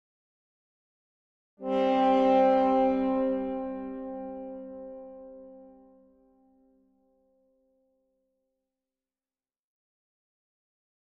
Tuba Tone 3 - Duet, Lower